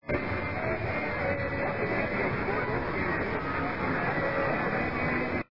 And this one with sports talk, "(something) sports leader"... again,